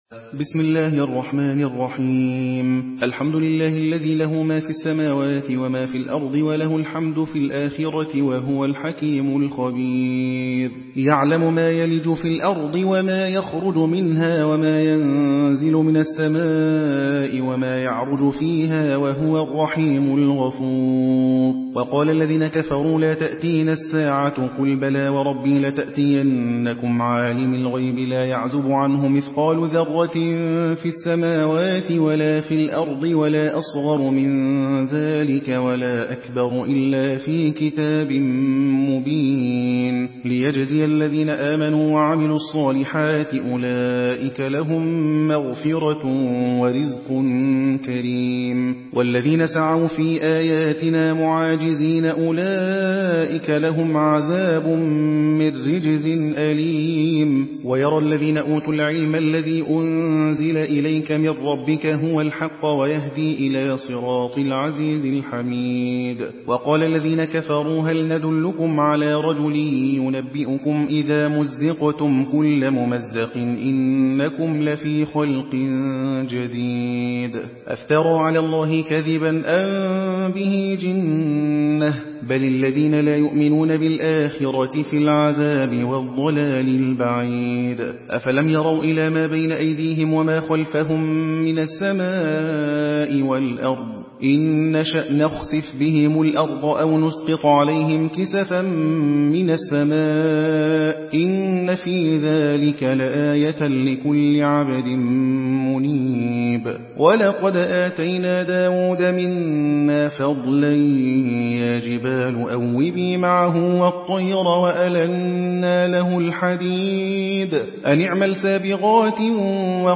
حدر حفص عن عاصم